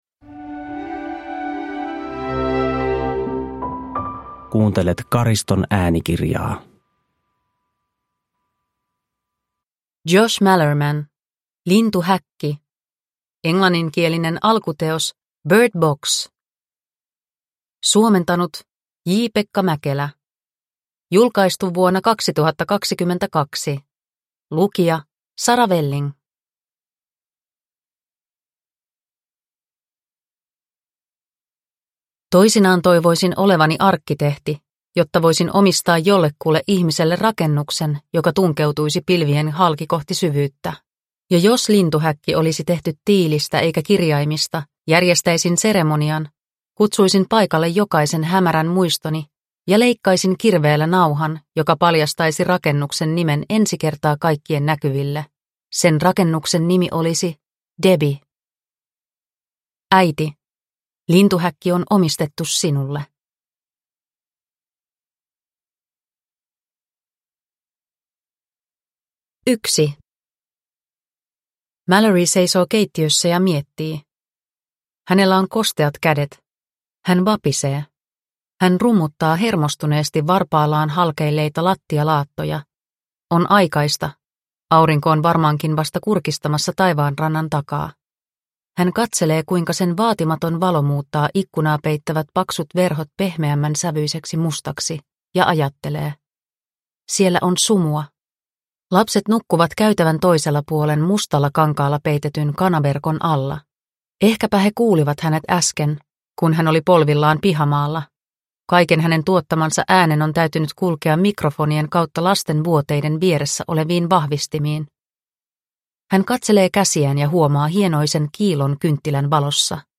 Lintuhäkki – Ljudbok – Laddas ner